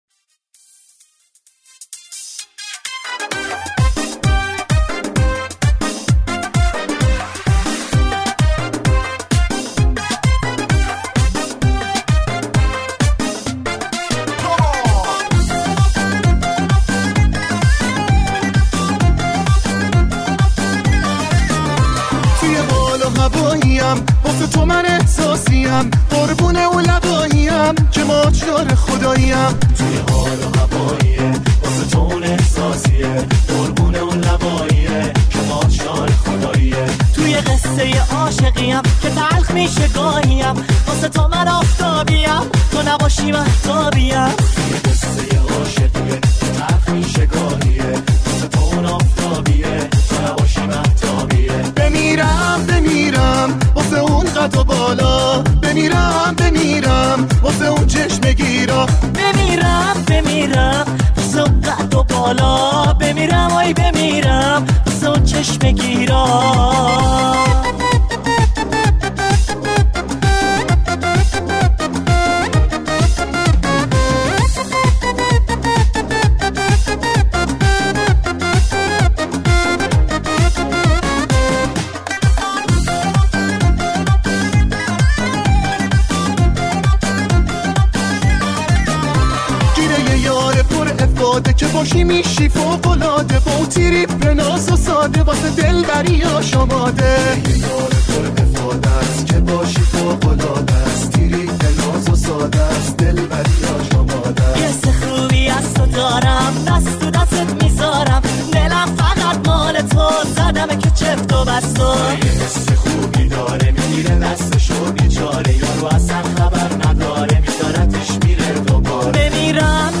آهنگ شاد بندری آهنگ شاد پارتی